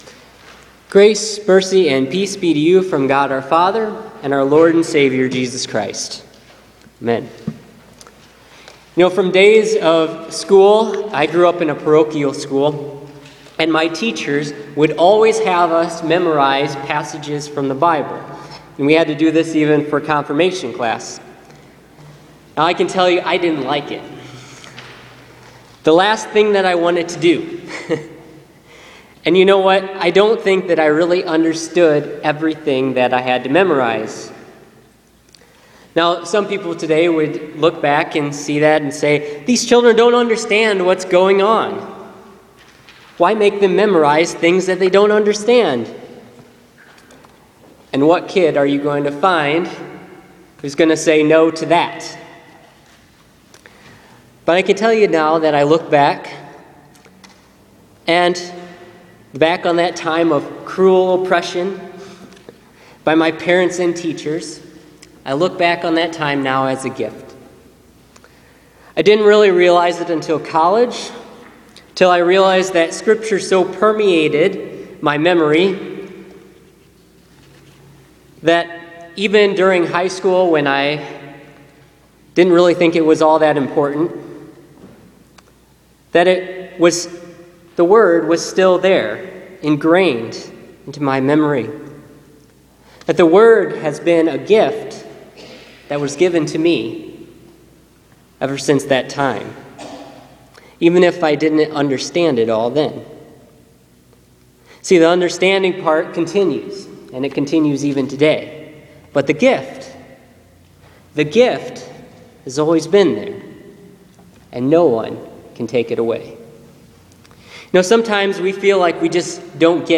Listen to this week’s sermon from John 16:12-33 for the fifth Sunday of Easter.